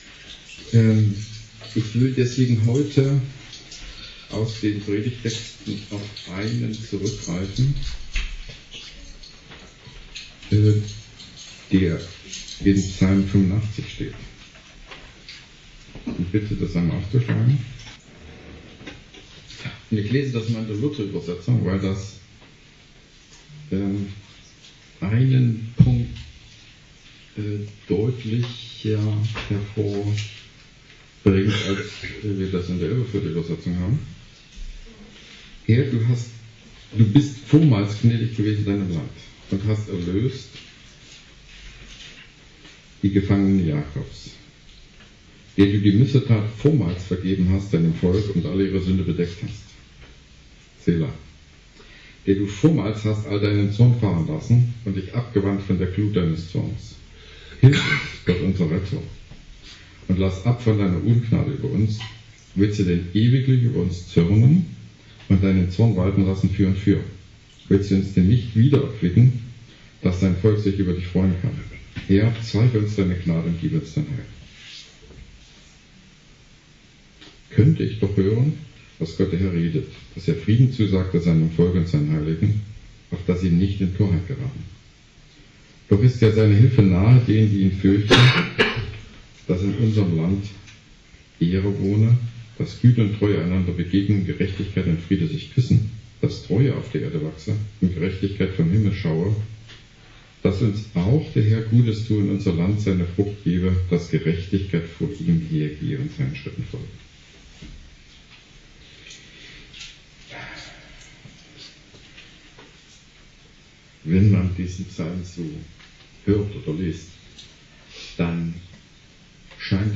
spricht in seiner Predigt über Psalm 85,1-14